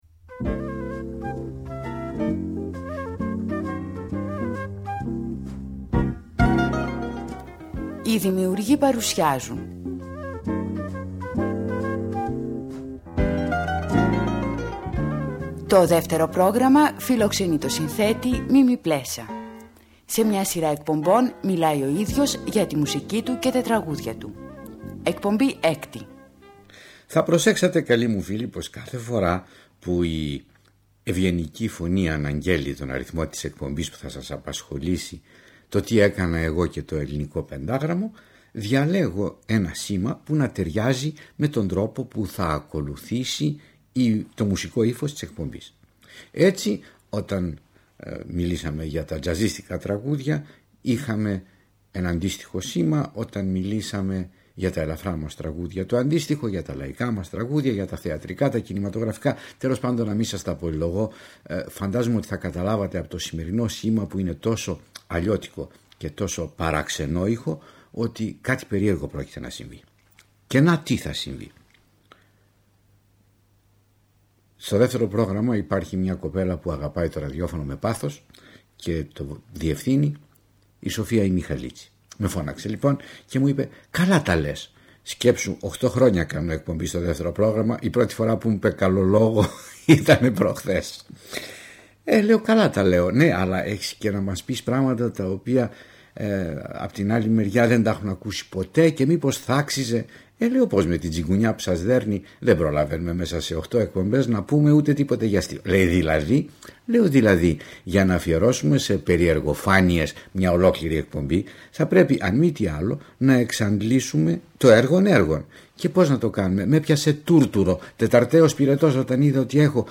Στις εκπομπές αυτές, ο μεγάλος συνθέτης αυτοβιογραφείται, χωρίζοντας την έως τότε πορεία του στη μουσική, σε είδη και περιόδους, διανθίζοντας τις αφηγήσεις του με γνωστά τραγούδια, αλλά και με σπάνια ηχητικά ντοκουμέντα.